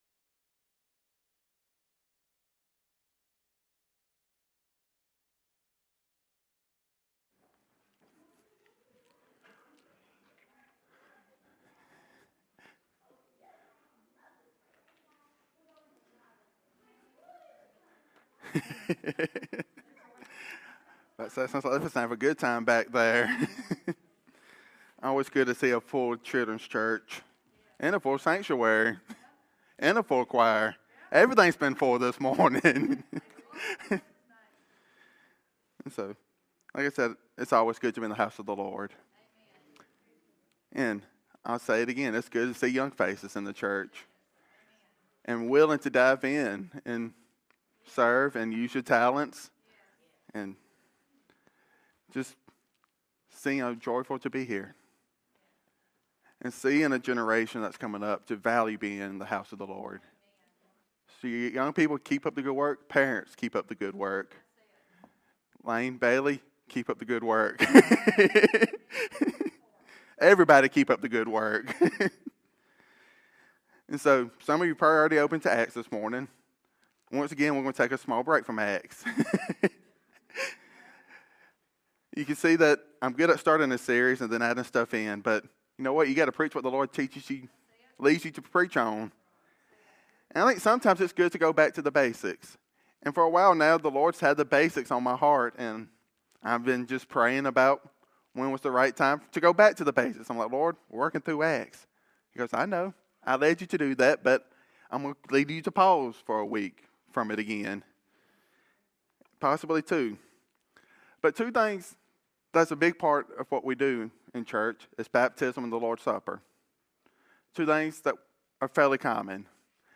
Drawing from the original Greek meaning of baptism and the historical transition from Passover to the Lord's Supper, this sermon challenges us to see these ordinances not as empty rituals, but as powerful symbols of our immersion in the Holy Spirit and our participation in the finished work of Christ.